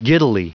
Prononciation du mot giddily en anglais (fichier audio)
Prononciation du mot : giddily